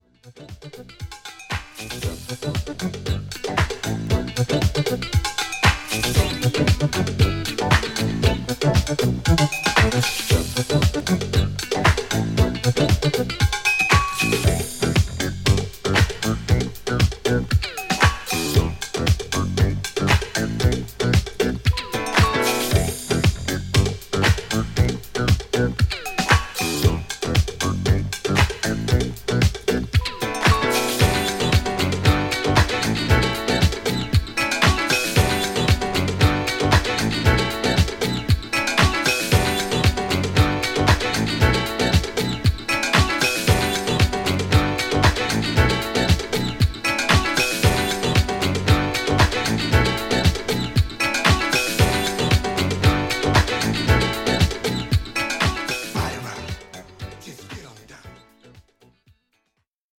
SOUL / FUNK / RARE GROOVE / DISCO